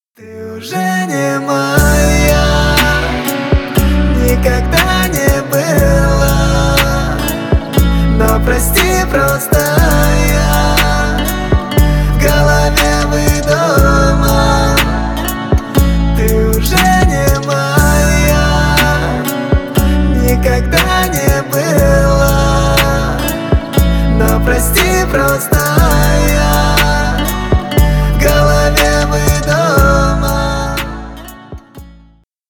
Поп Музыка # Рэп и Хип Хоп
грустные